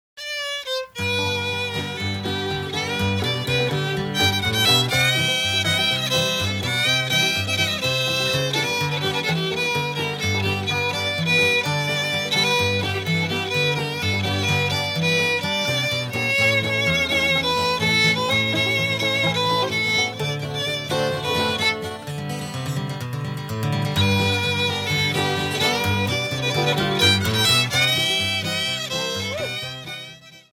Down Yonder: Old Time String Band Music from Georgia
fiddle
guitar
banjo